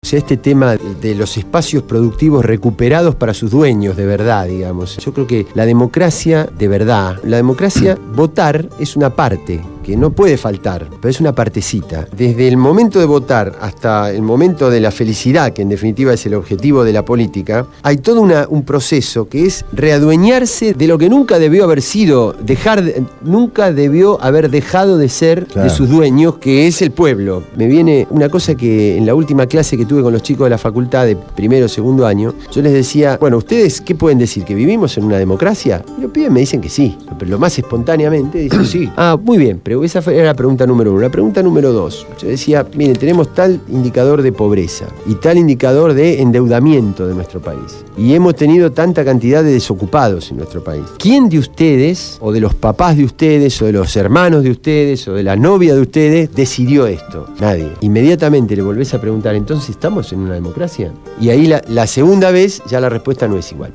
Carlos Raimundi, diputado del bloque Solidaridad e Igualdad (SI) estuvo en el piso de Radio Gráfica durante la emisión del programa «Cambio y Futuro» (Jueves, de 20 a 22 hs).